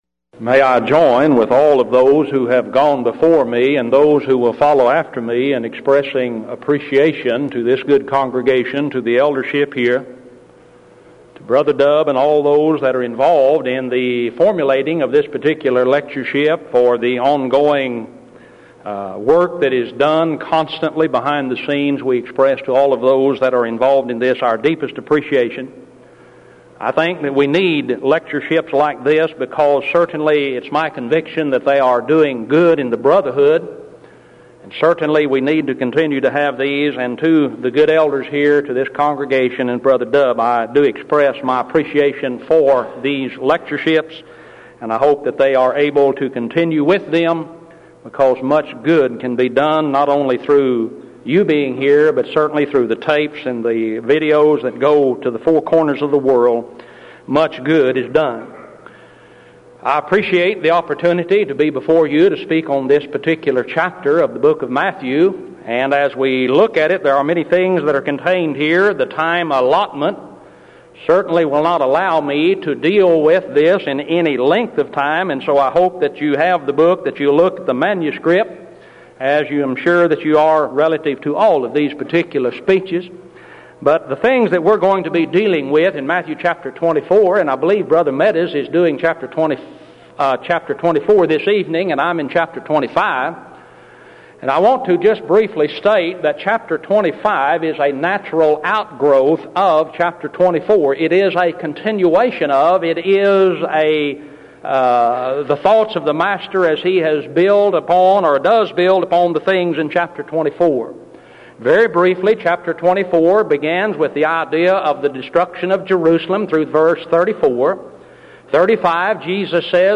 Event: 1995 Denton Lectures
lecture